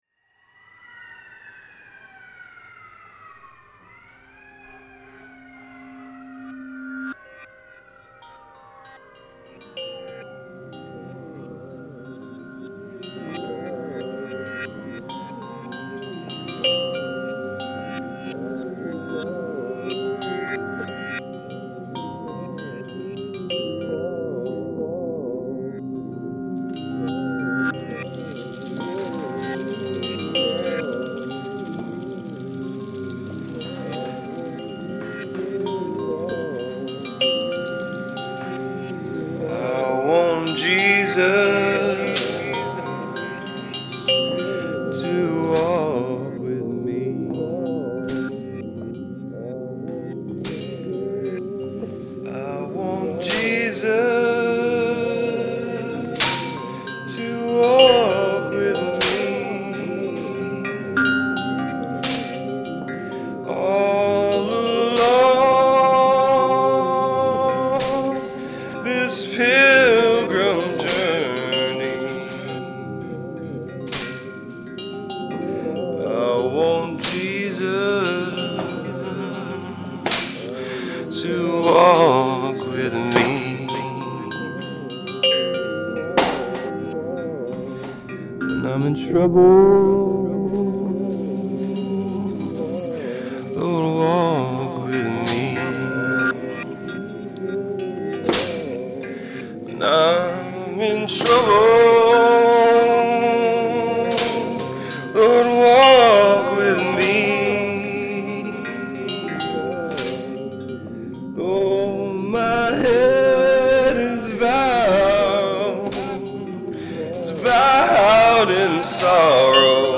hymn studies
I Want Jesus to Walk With Me (live version feat. kids bells choir)